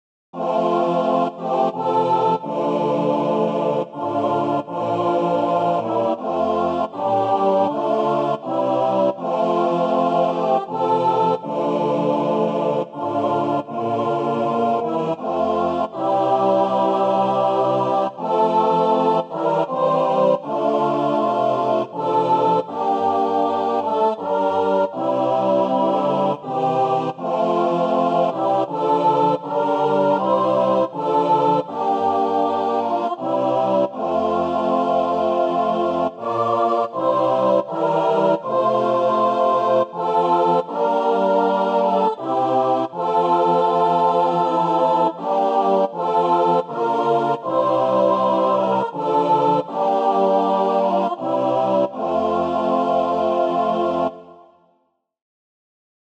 SATB
Voicing/Instrumentation: SATB We also have other 15 arrangements of " Savior, Redeemer of My Soul ".